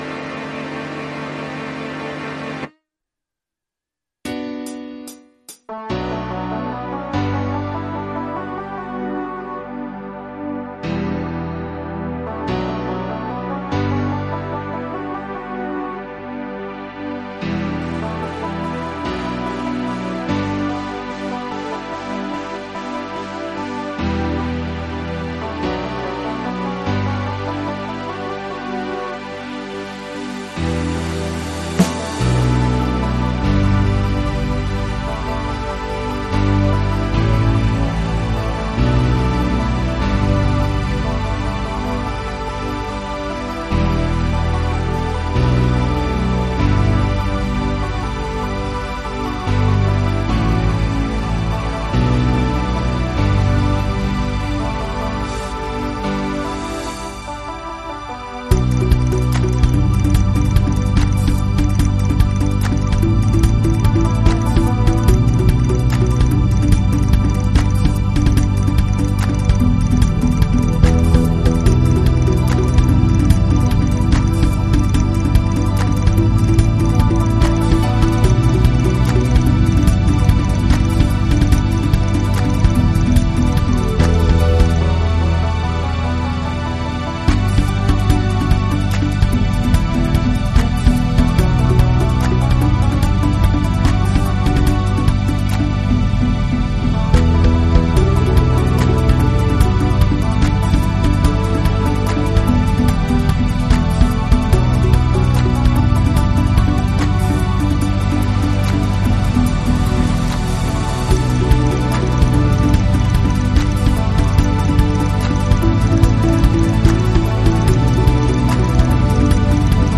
version instrumentale multipistes
au format MIDI Karaoke pro.